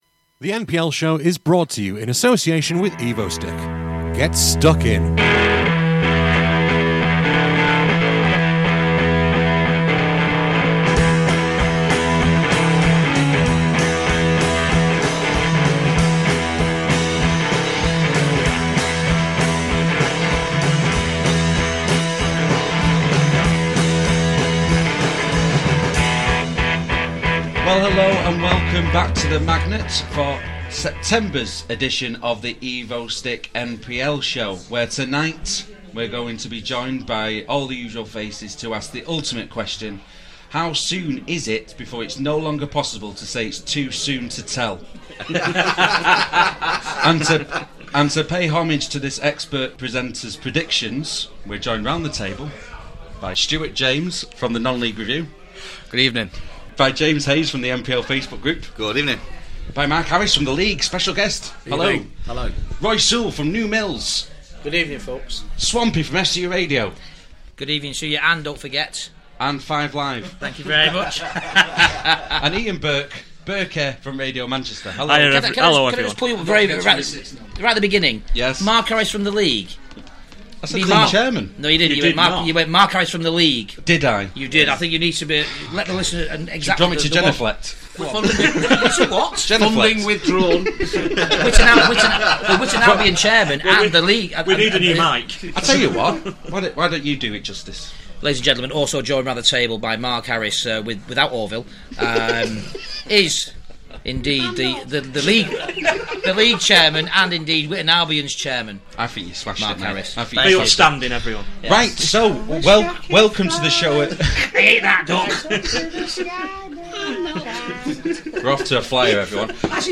With Thanks: This programme was recorded at the Magnet in Stockport on Wednesday 1st October 2014.